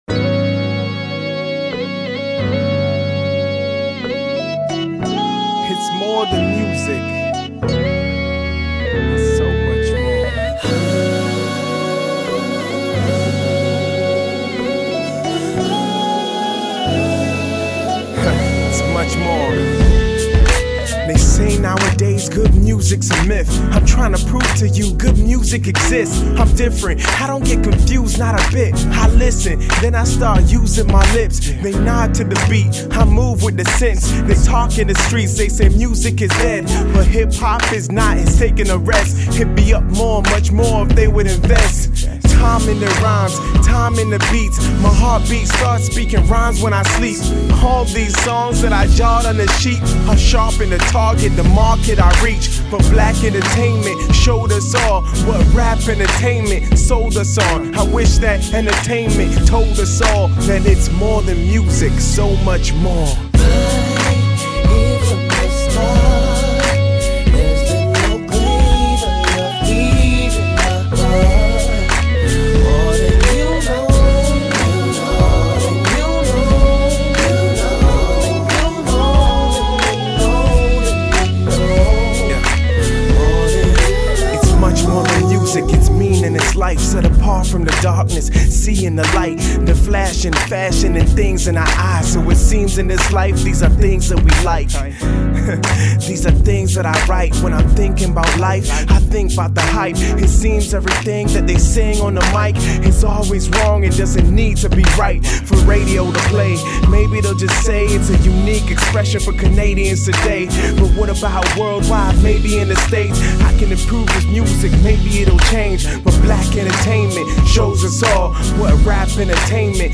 spit a few bars